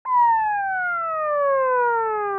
ambulance.mp3